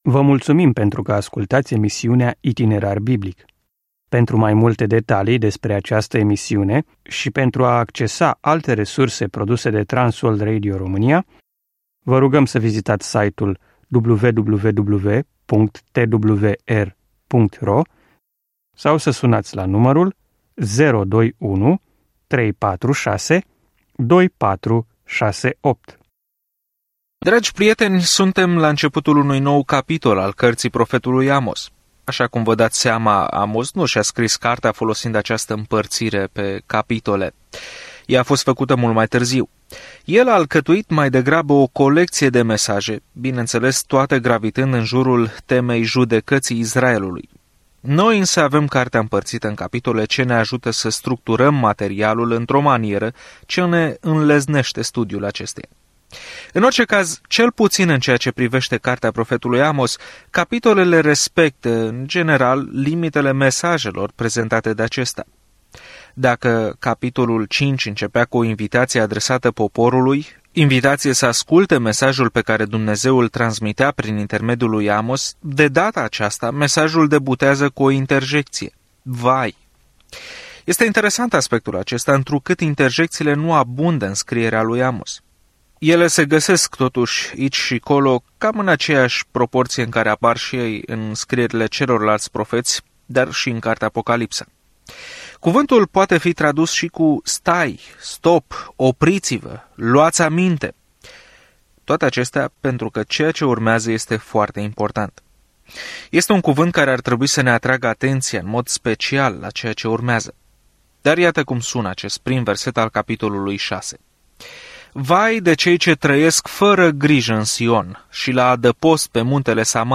Scriptura Amos 6:1-11 Ziua 8 Începe acest plan Ziua 10 Despre acest plan Amos, un predicator de țară, merge în orașul mare și condamnă căile lor păcătoase, spunând că toți suntem responsabili în fața lui Dumnezeu, conform luminii pe care El ne-a dat-o. Călătoriți zilnic prin Amos în timp ce ascultați studiul audio și citiți versete selectate din Cuvântul lui Dumnezeu.